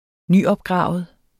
Udtale [ ˈnyʌbˌgʁɑˀvəð ]